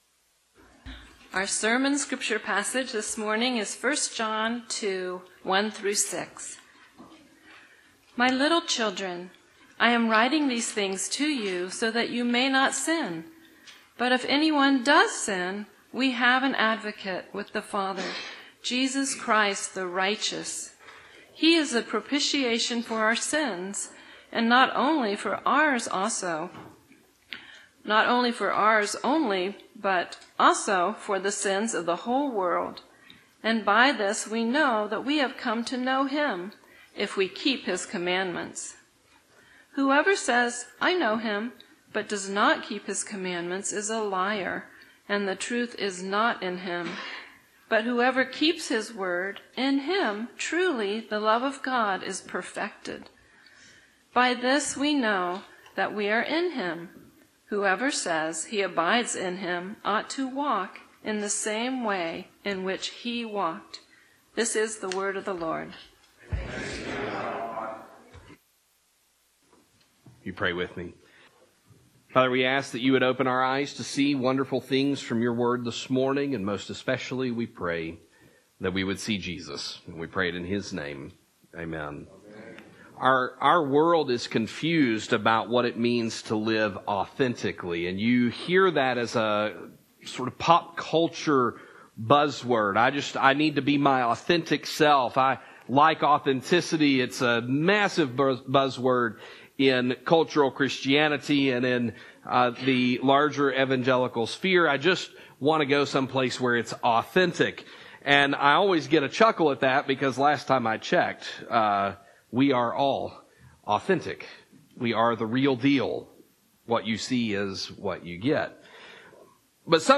Sermons from Christ the King Presbyterian Church (PCA) in Austin, TX